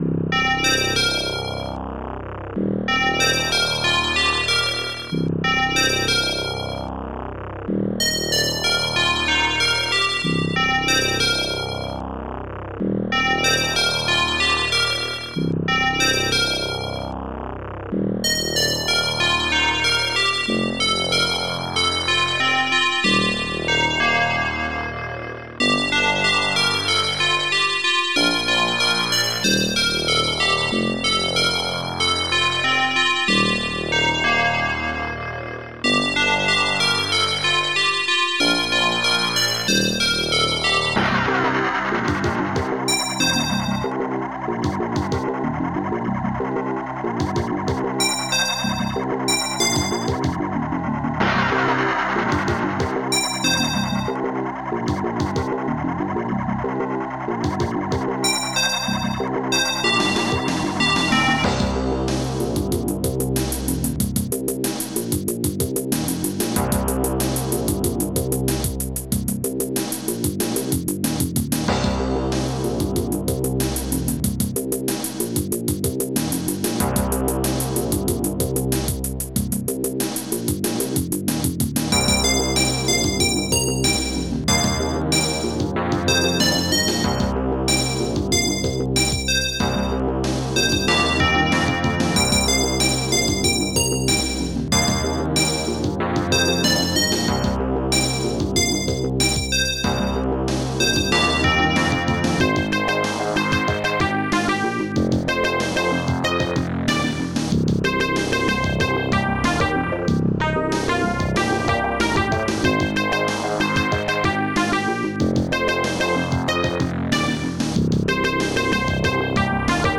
Star Tracker/StarTrekker Module
2 channels